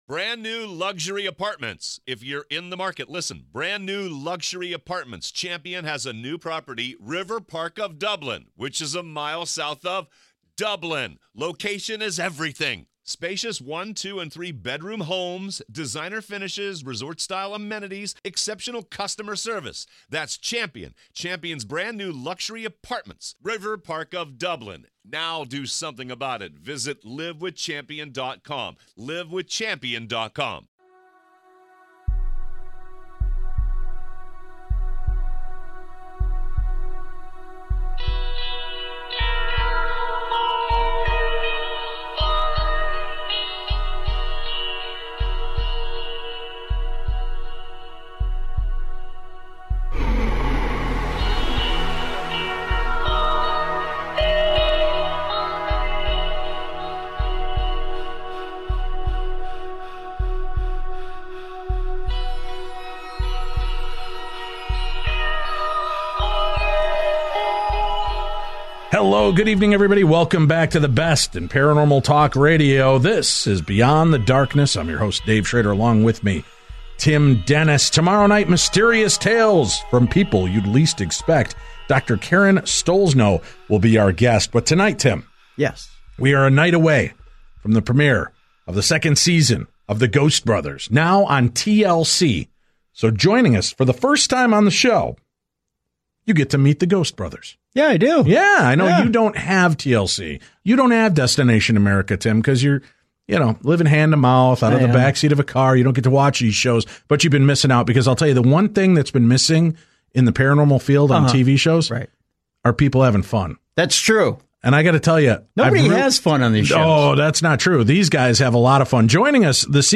paranormal talk radio